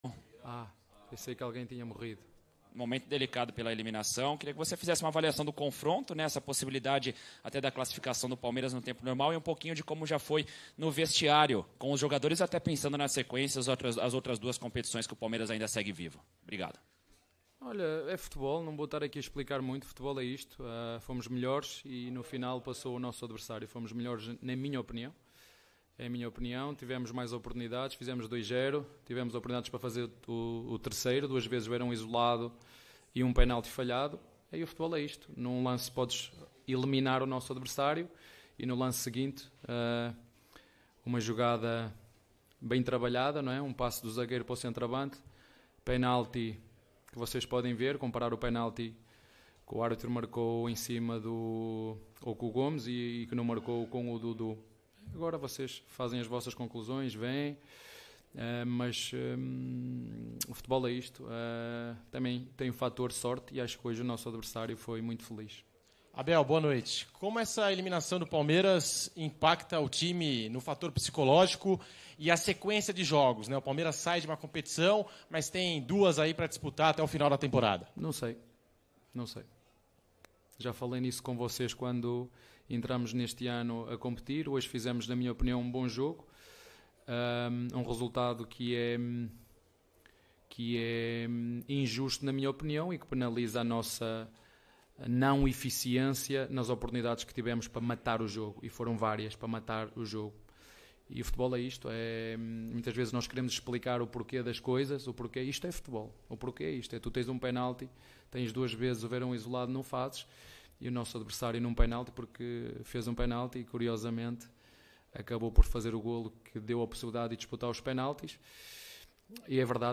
COLETIVA-ABEL-FERREIRA-_-PALMEIRAS-X-SAO-PAULO-_-COPA-DO-BRASIL-2022.mp3